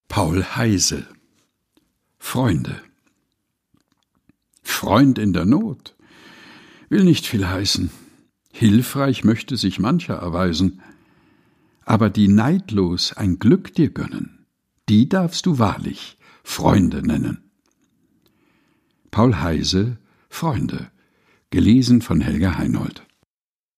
Ohrenweide ist der tägliche Podcast mit Geschichten, Gebeten und Gedichten zum Mutmachen und Nachdenken - ausgesucht und im heimischen Studio vorgelesen